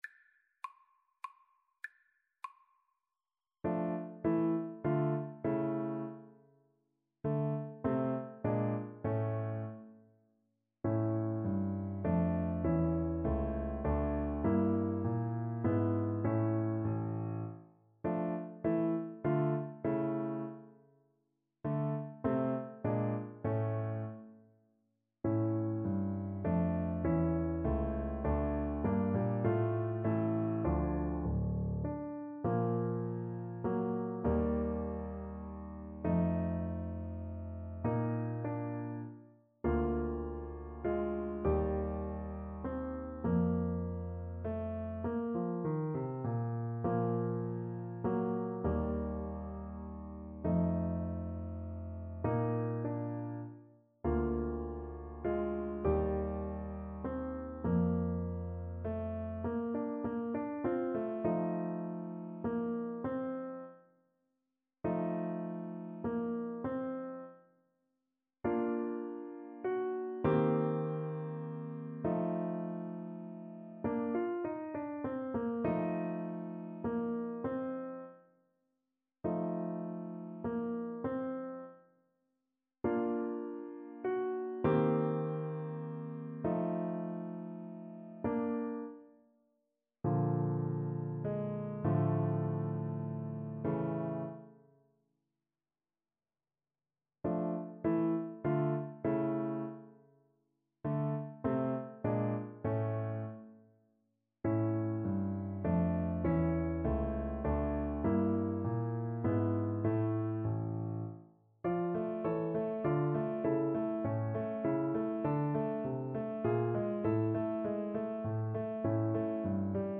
3/4 (View more 3/4 Music)
Allegretto